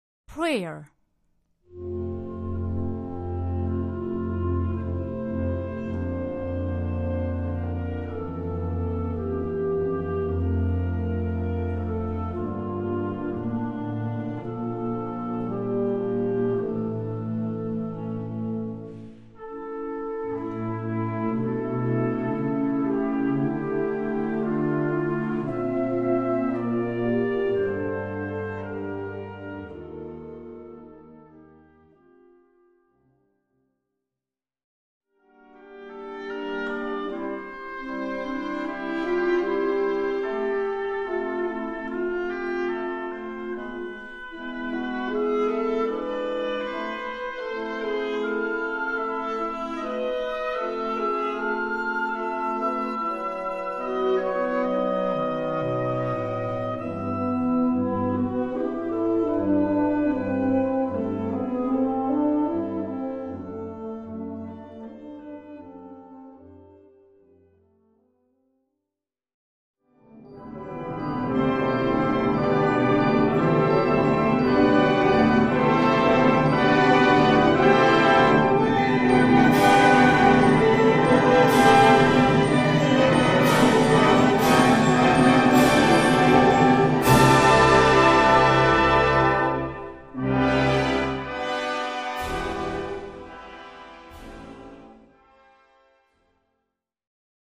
Gattung: Hymne - Choral
Besetzung: Blasorchester
wundervoller besinnlicher Choral